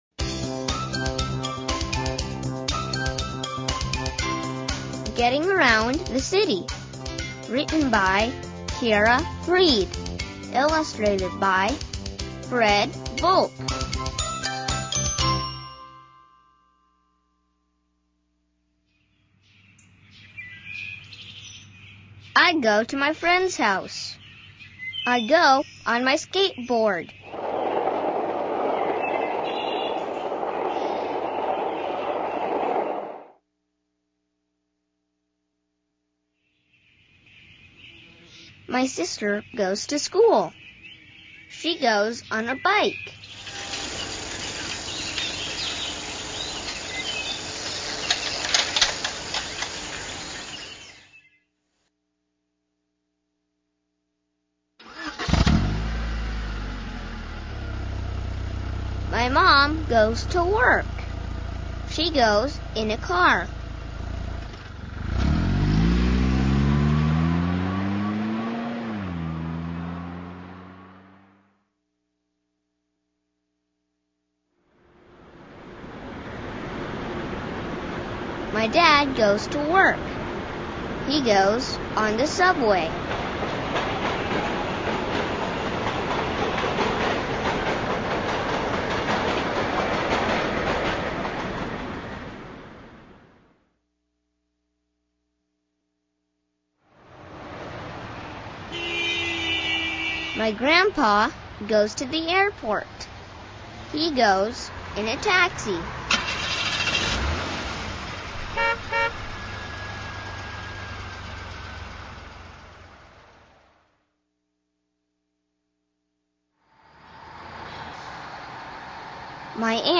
听力练习：